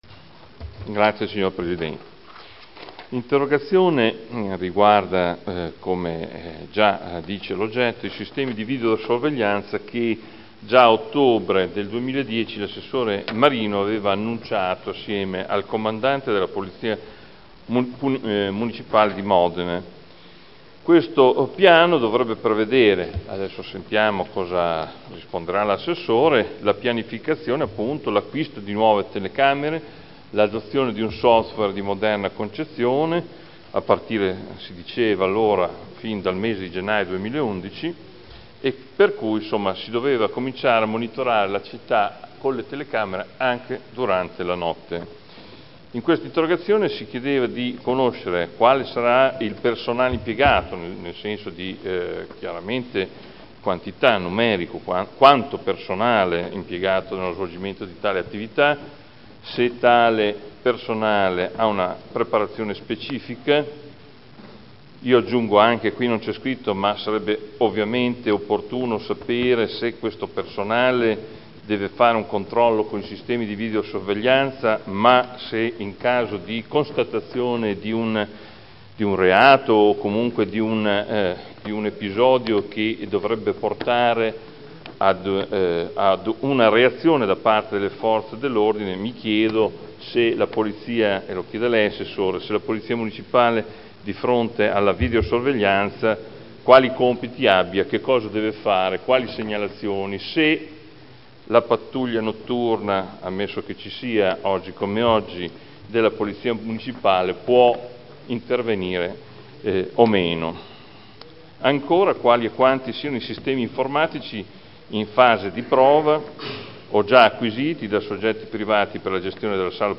Adolfo Morandi — Sito Audio Consiglio Comunale